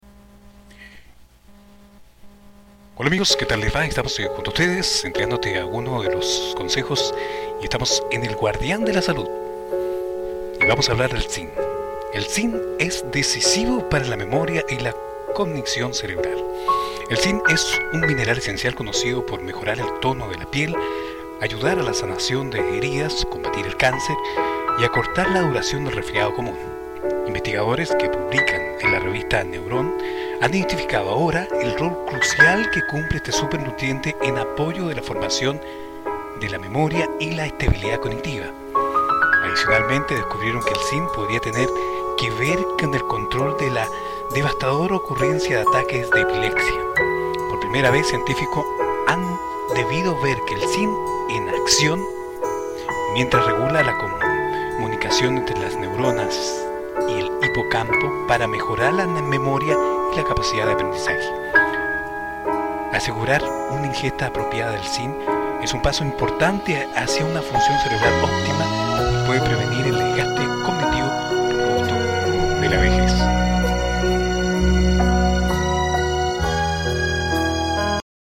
Masculino
Espanhol - Chile